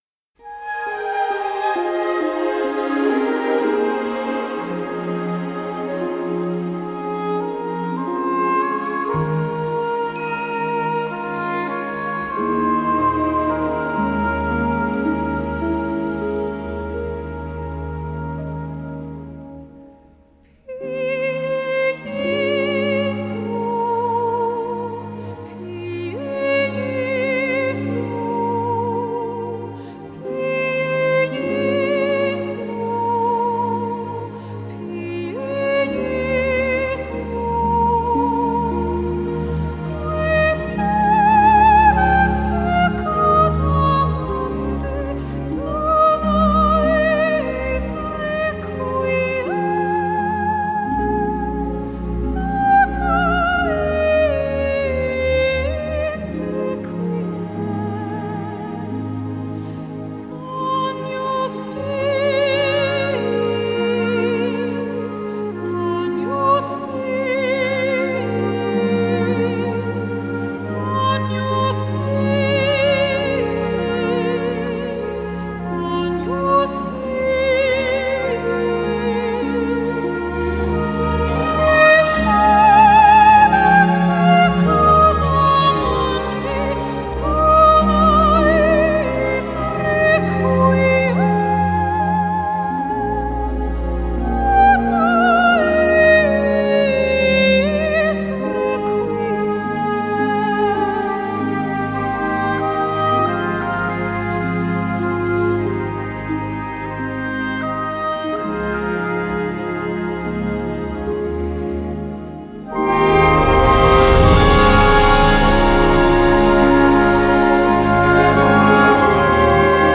Genre: Pop
Accordion